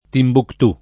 Timbuctù [ timbukt 2+ ] → Tombouctou